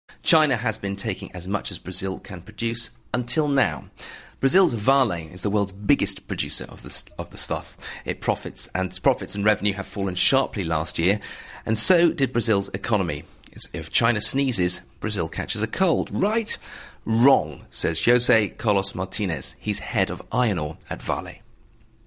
【英音模仿秀】中国经济放缓 巴西铁矿受挫？